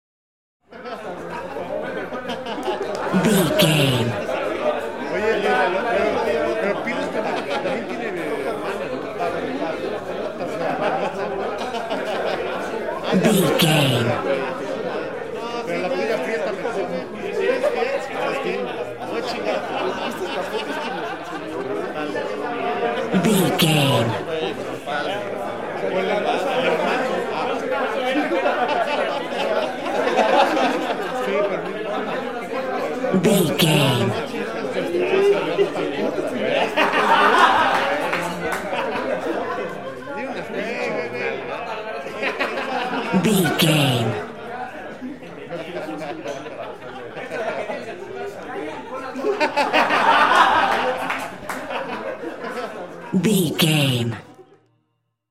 Crowd party laughs
Sound Effects
funny
cheerful/happy
ambience